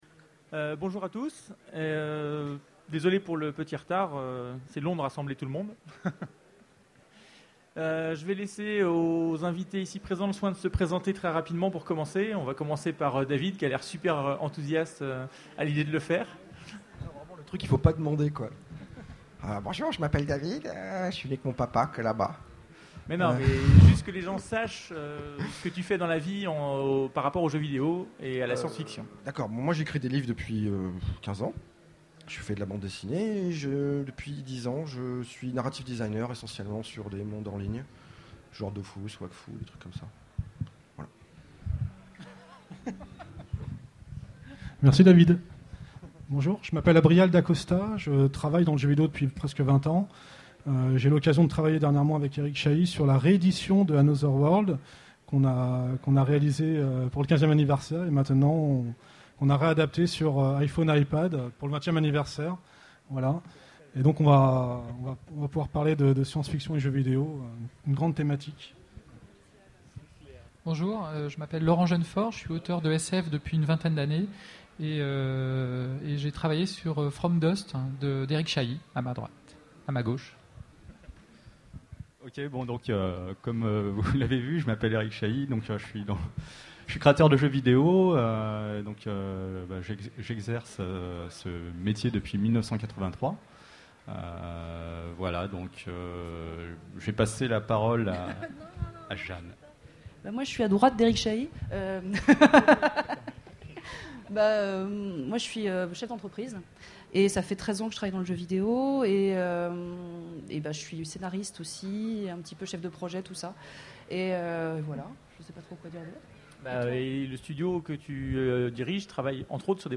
Utopiales 2011 : Conférence SF et jeux vidéo, un angle d'approche unique